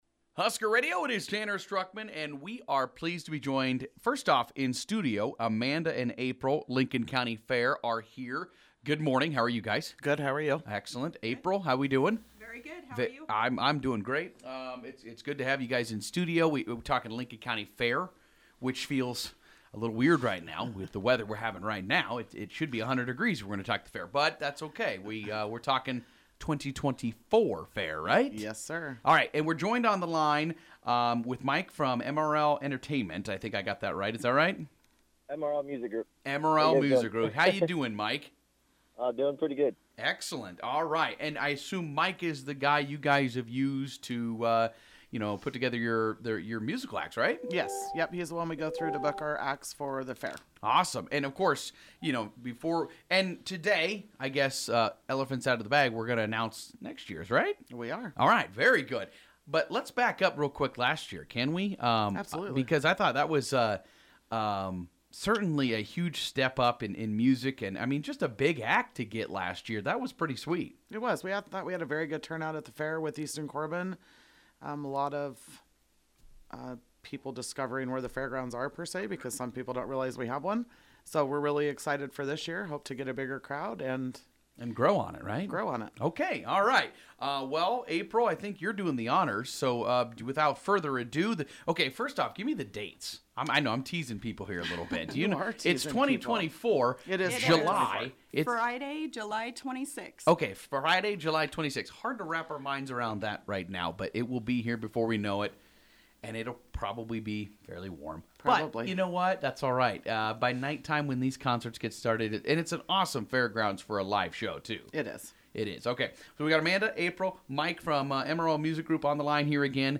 Lincoln-County-Fairgrounds-Interview.mp3